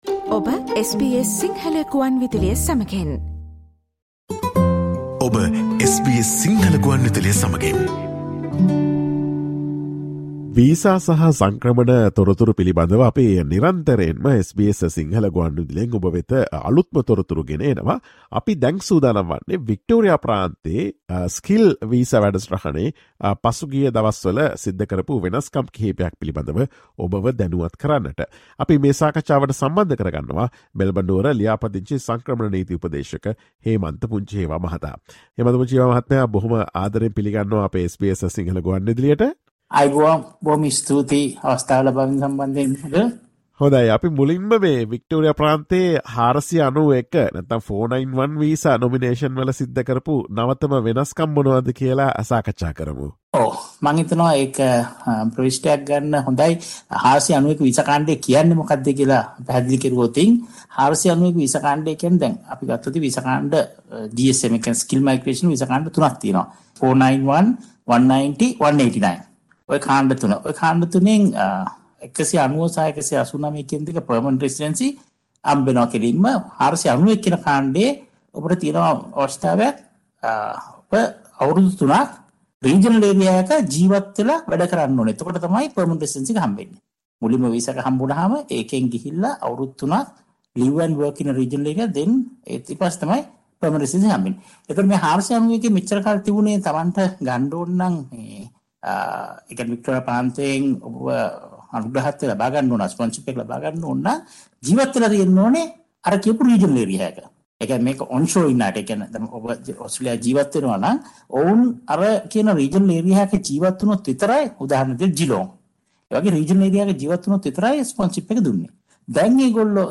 Listen to SBS Sinhala Radio's discussion on the latest changes in Victoria Skilled Visa Program.